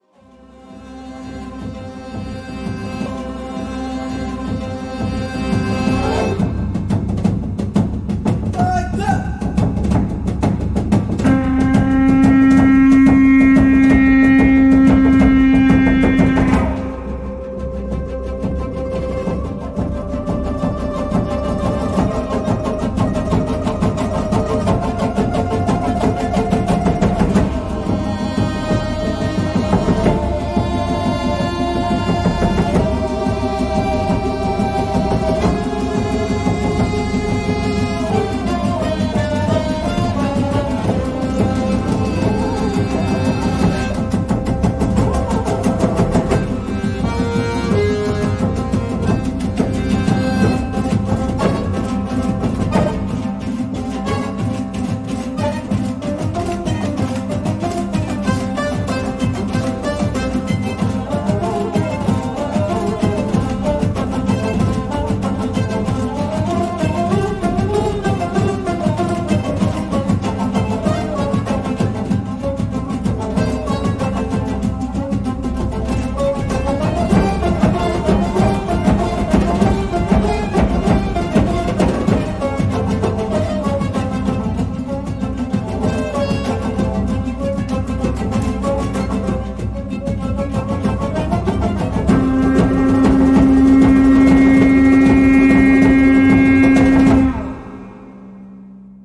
ұлт аспаптар ансамбльдеріне арналған шығармалары